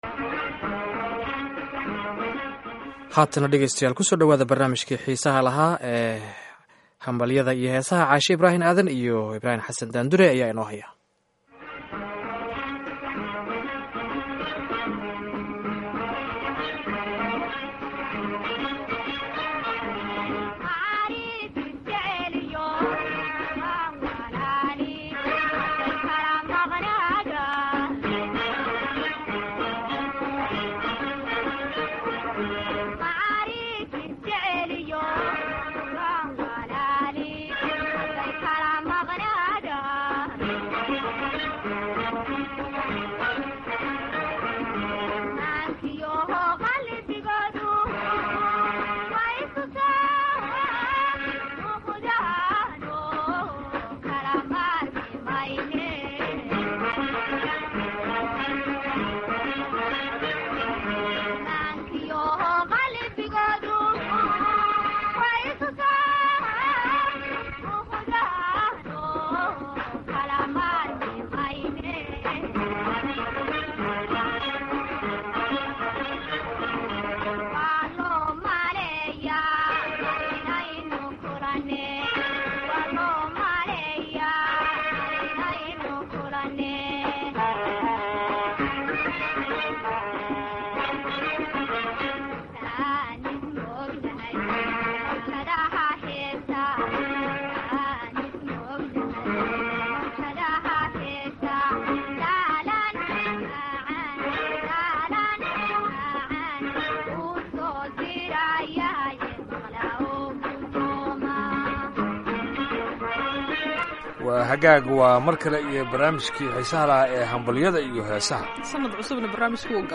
Waxaadna ku dhageysan doontaan, dhambaalada tahniyadaha aroosyada, dhalashada iyo heeso macaan oo aan ugu talagalnay barnaamijka.